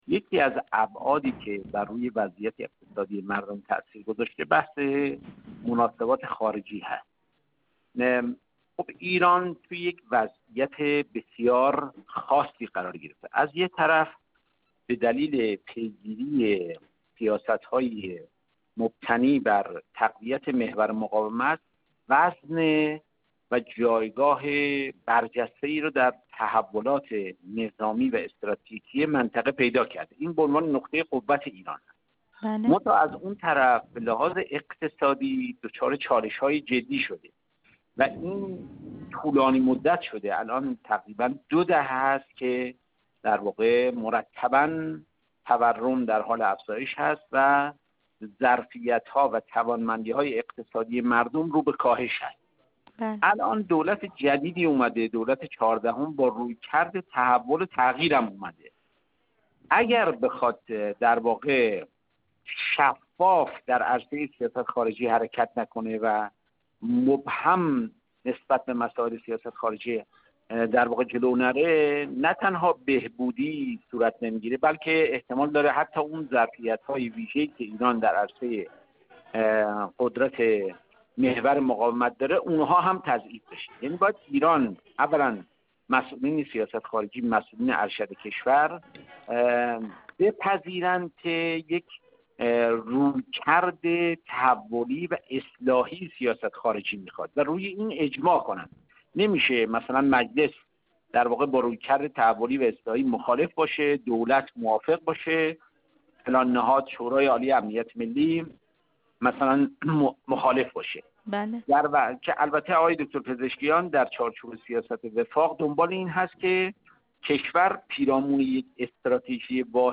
گفت‌و گو